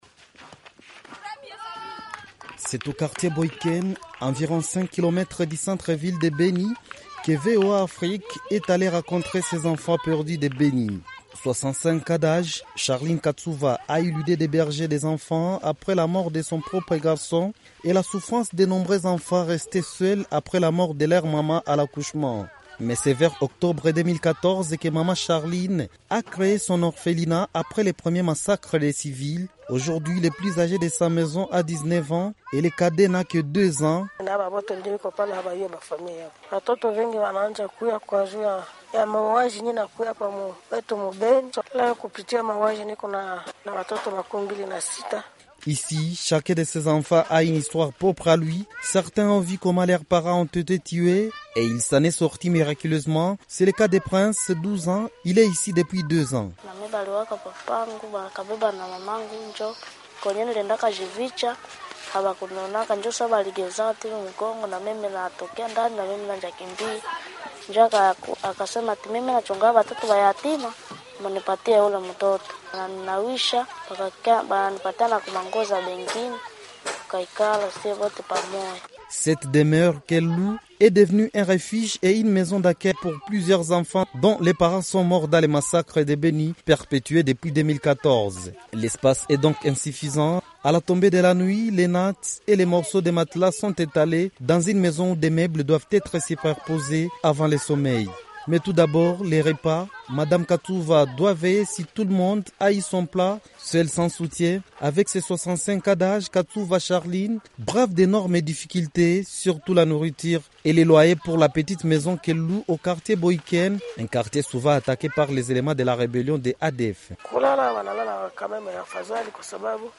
VOA Afrique est allée à la rencontre de ces enfants à l'orphelinat "Maman Marie", au quartier Boiken, à environ 5 kilomètres du centre-ville de Beni.
Reportage